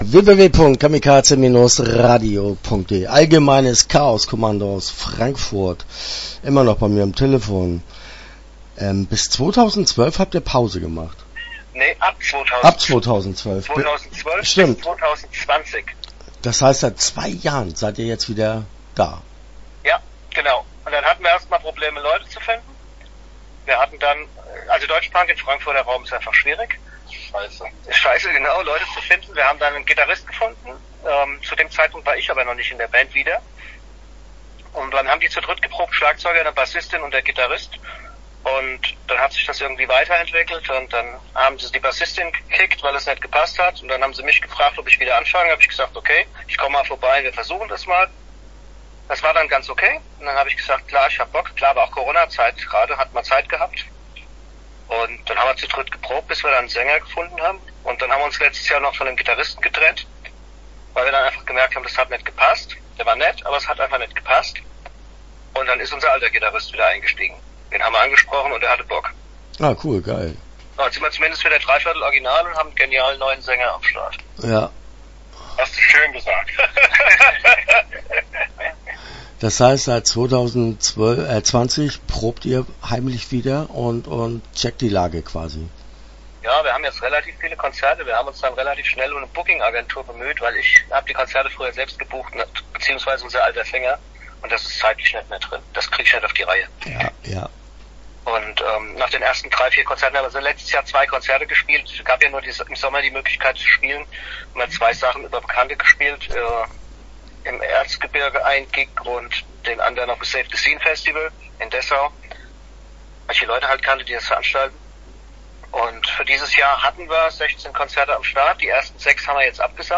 Start » Interviews » Allgemeines Chaos Kommando - A.C.K.